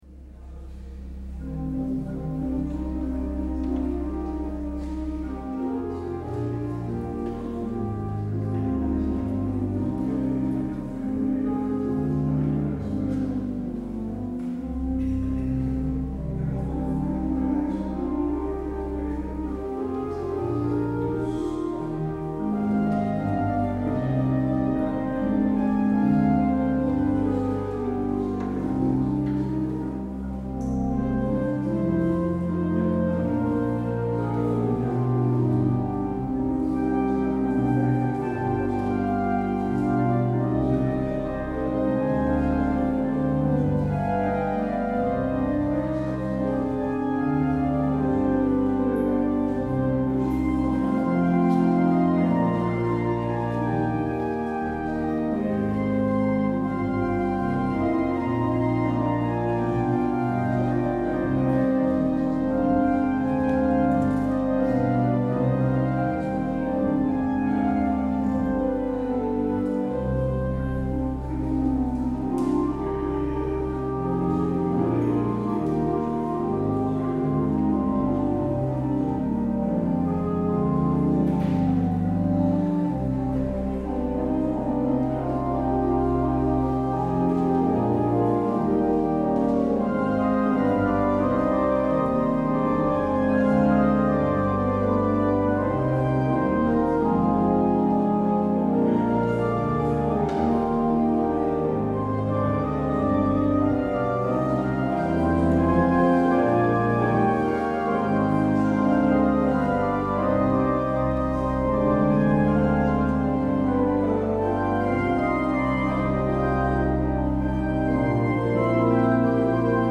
 Luister deze kerkdienst hier terug: Alle-Dag-Kerk 12 november 2024 Alle-Dag-Kerk https